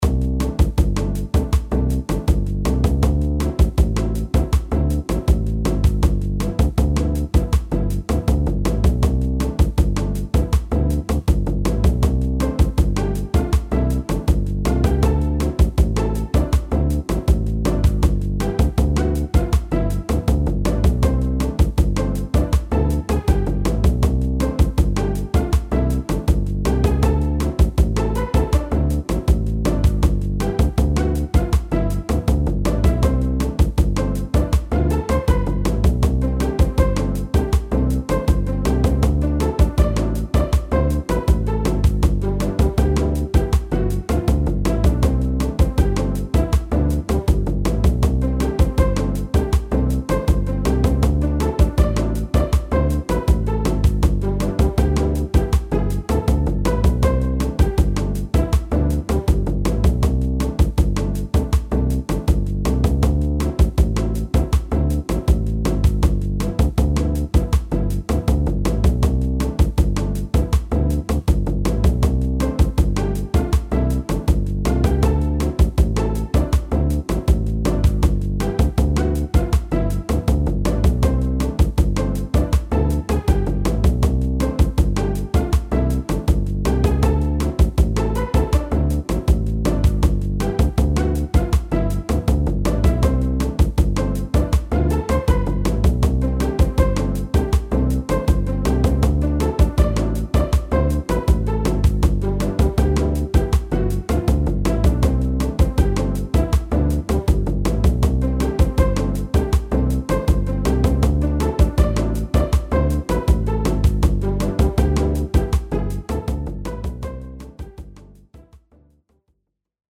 Cinematic
CuteHumorous